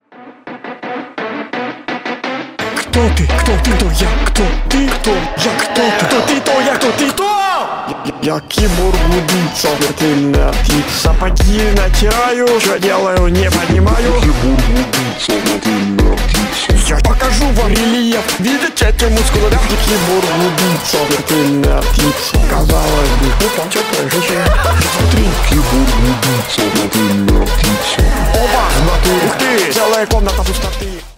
Ремикс
весёлые
клубные